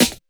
41 SD 02  -R.wav